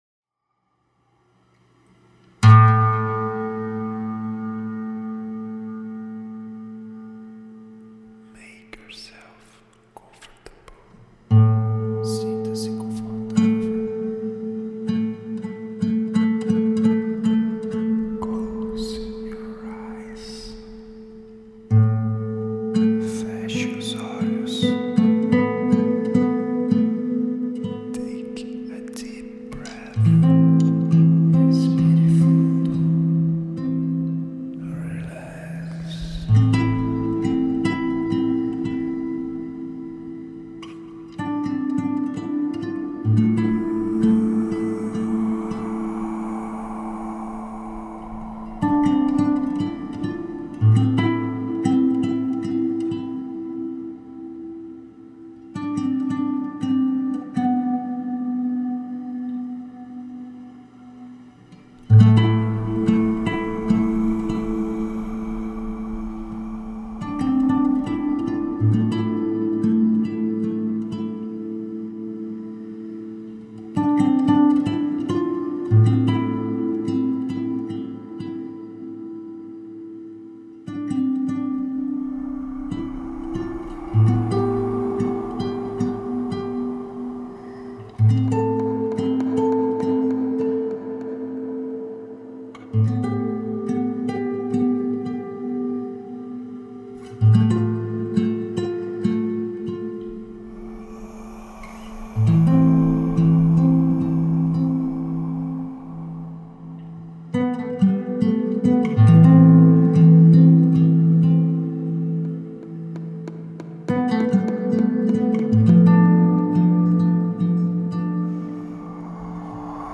Gênero: Dream.